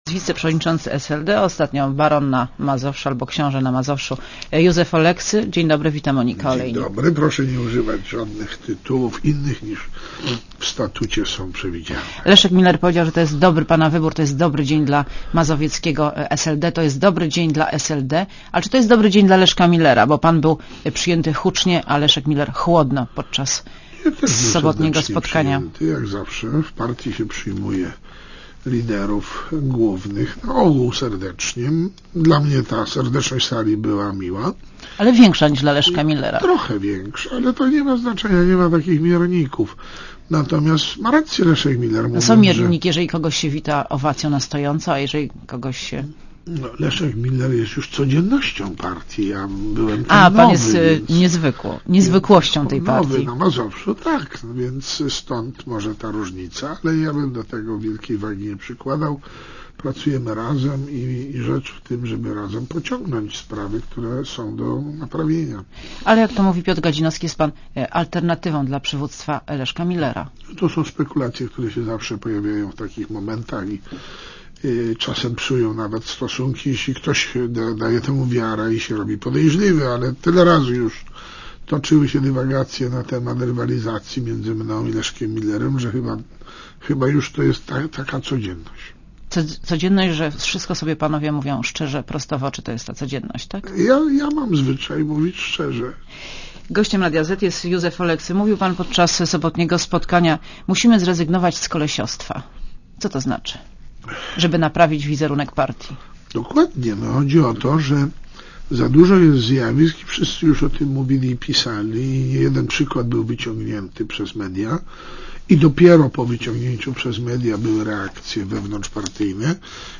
Posłuchaj wywiadu (2,9 MB) Gościem Radia Zet jest wiceprzewodniczący SLD, ostatnio „baron na Mazowszu” albo „książę na Mazowszu” Józef Oleksy.